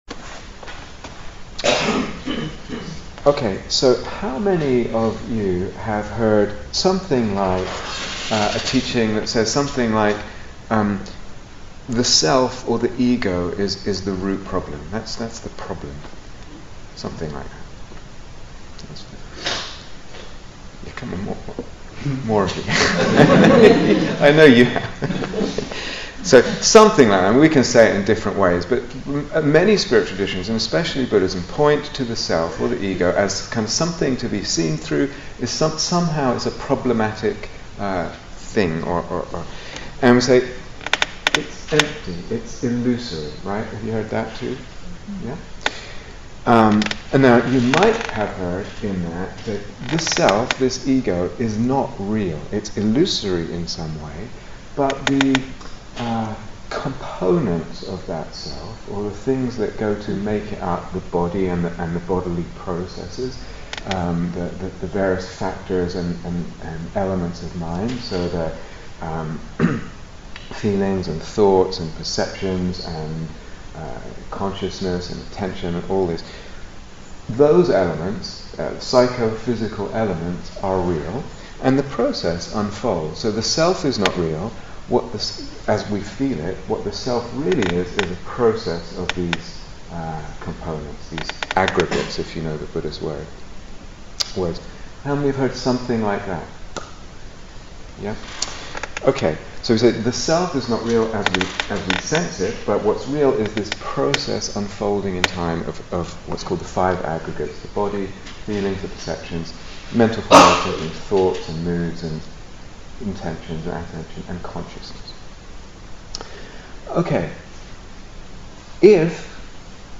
Retreat/Series Day Retreat, London Insight 2014